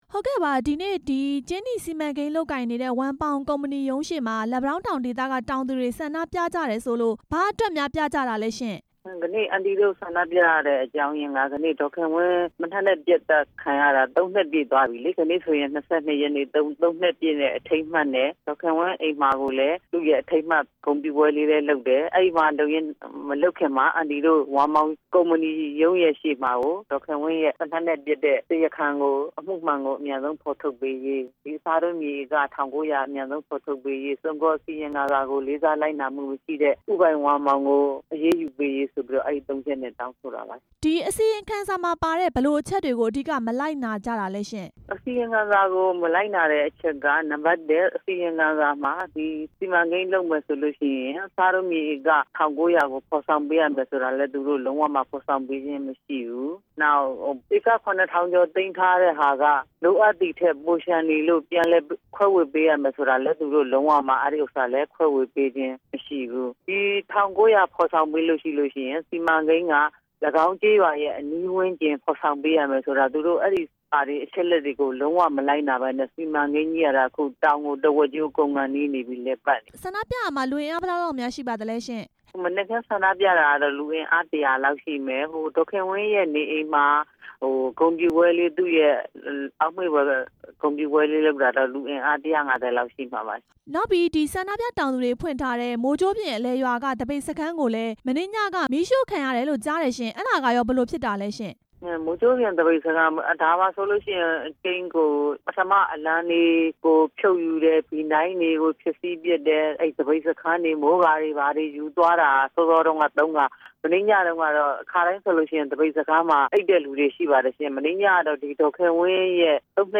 သပိတ်စခန်းဖျက်စီးခံရတဲ့အကြောင်း ဆက်သွယ်မေးမြန်းချက်